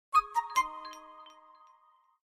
Categoría Notificaciones